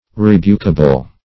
Search Result for " rebukable" : The Collaborative International Dictionary of English v.0.48: Rebukable \Re*buk"a*ble\ (r[-e]*b[=u]k"[.a]*b'l), a. Worthy of rebuke or reprehension; reprehensible.